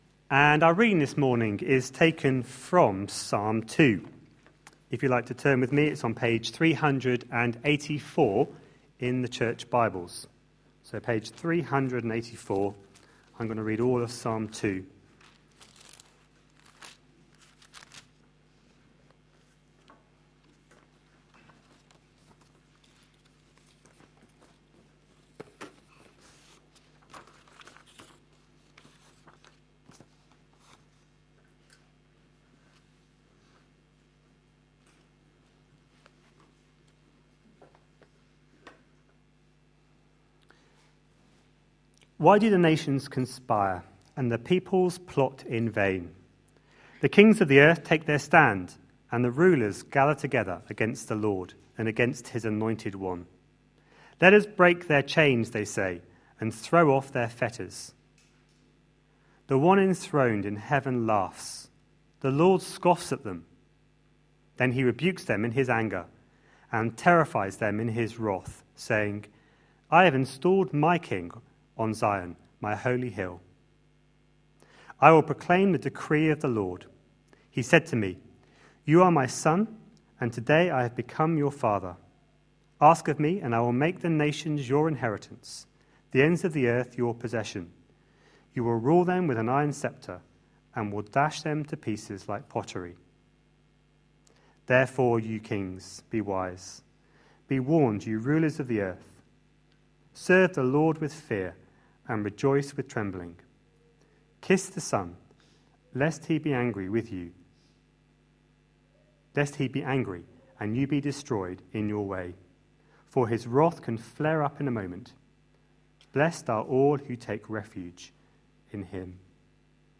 A sermon preached on 26th January, 2014, as part of our The gospel is the reason series.